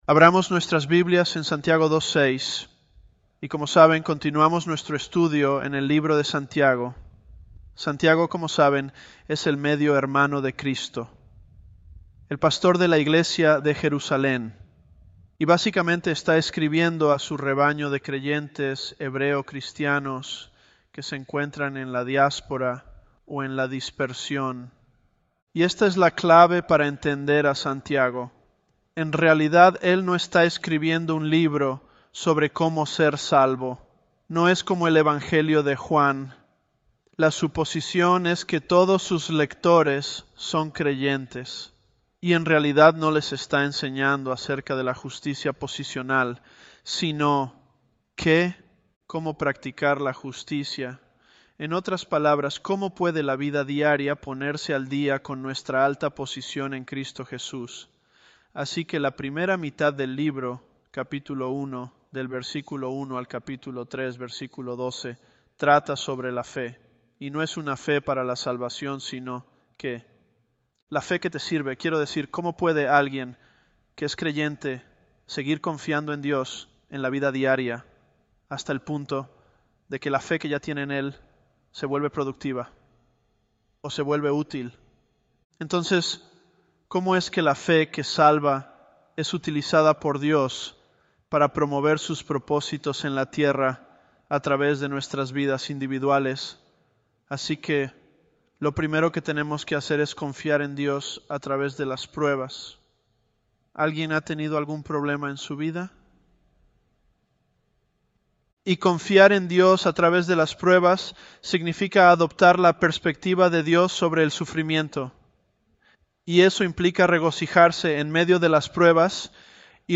Elevenlabs_James009.mp3